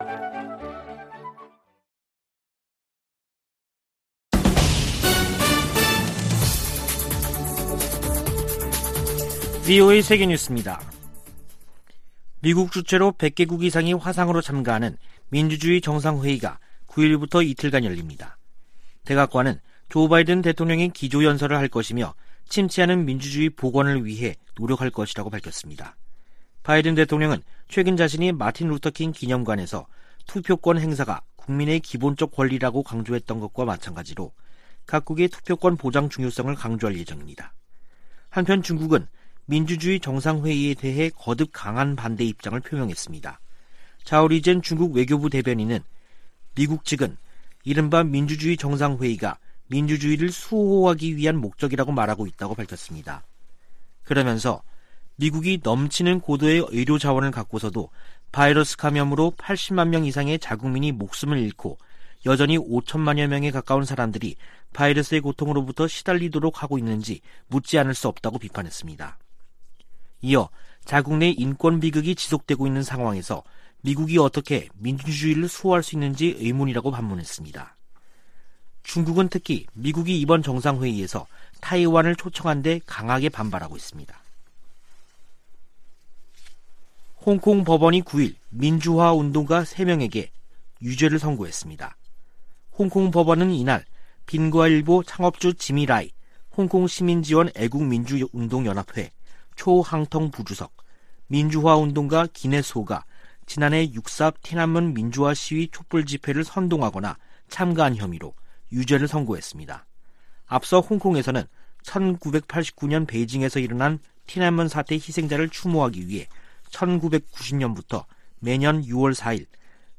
VOA 한국어 간판 뉴스 프로그램 '뉴스 투데이', 2021년 12월 9일 3부 방송입니다. 미국 공화당 하원의원 35명이 한국전쟁 종전선언을 반대하는 서한을 백악관에 보냈습니다. 한국 정부는 북한을 향해 종전선언 호응을 촉구하며 돌파구를 찾고 있지만 별다른 반응을 이끌어내지 못하고 있습니다. 미 상·하원 군사위가 합의한 2022회계연도 국방수권법안(NDAA) 최종안에는 '웜비어 법안' 등 한반도 안건이 대부분 제외됐습니다.